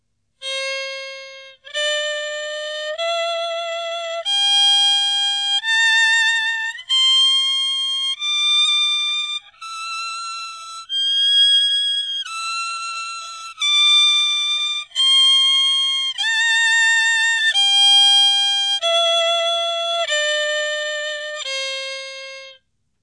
Đàn cò líu
[IMG]àn Cò Líu trong trẻo, chói sáng, biểu đạt những tình cảm đẹp đẽ, cao thượng, vui tươi, sôi nổi... có thể gay gắt, sắc nhọn nhất là ở những âm cao.